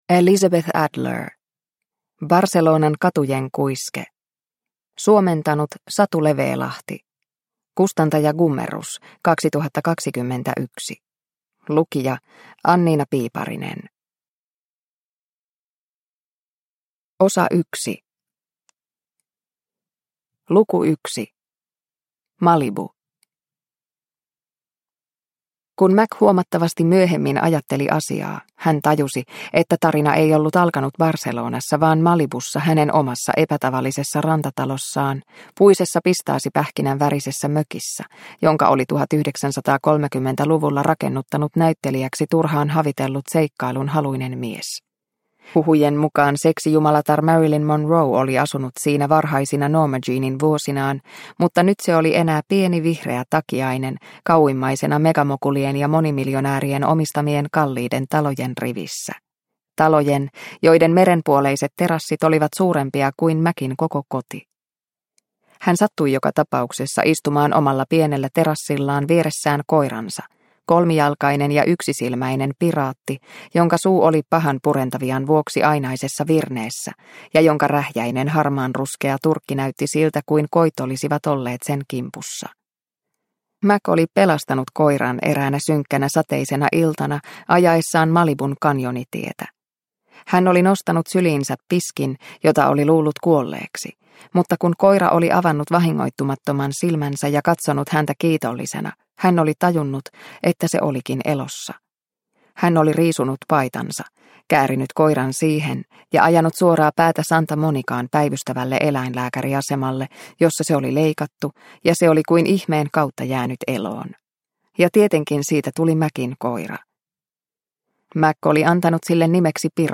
Barcelonan katujen kuiske – Ljudbok – Laddas ner